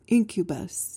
PRONUNCIATION:
(IN-kyuh-buhs)